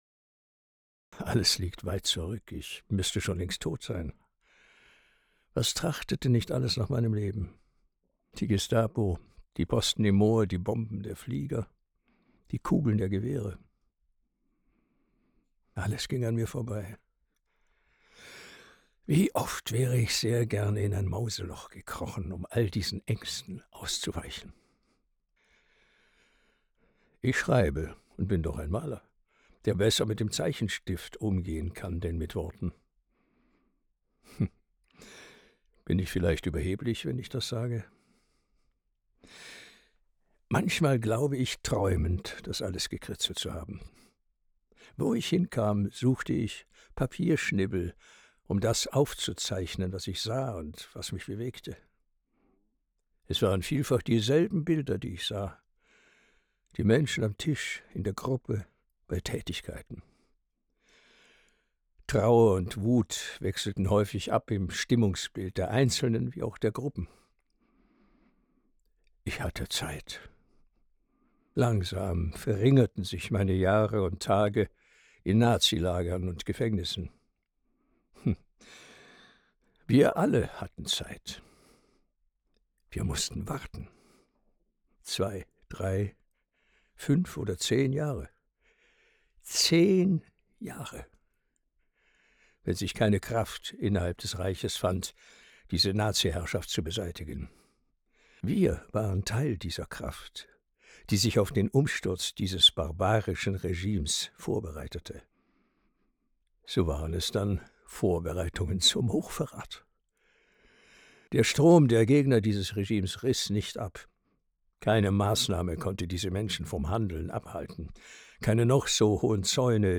Das M 990 ist ein Microtech Gefell Mikro.
Das M 990 ist moderner und rauschärmer.